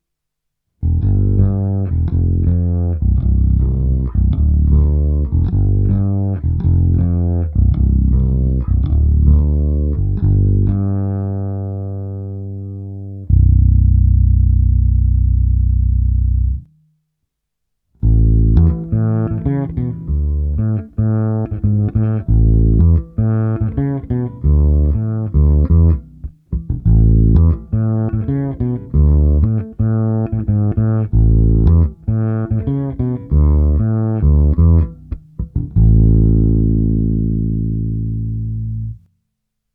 Kobylkový snímač